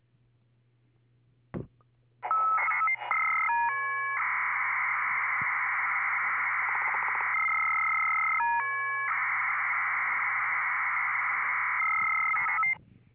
Sounds a little bit like some sort of maritime encryption.